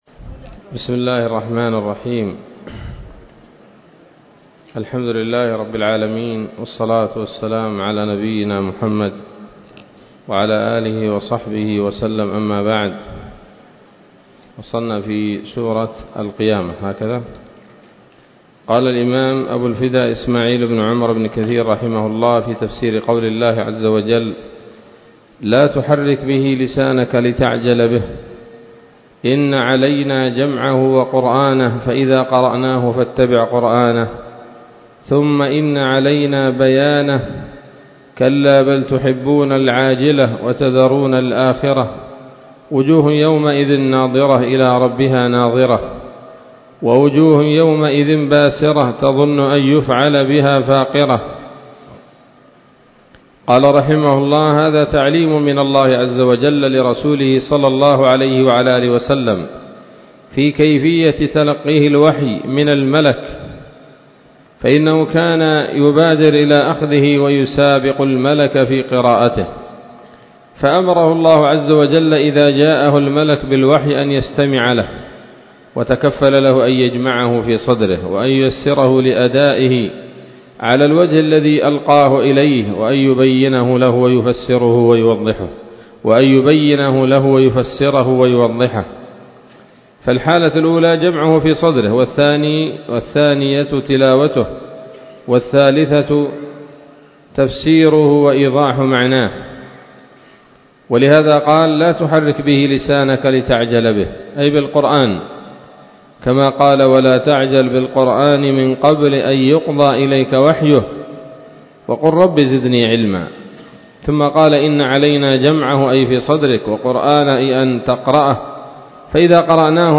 الدرس الثاني من سورة القيامة من تفسير ابن كثير رحمه الله تعالى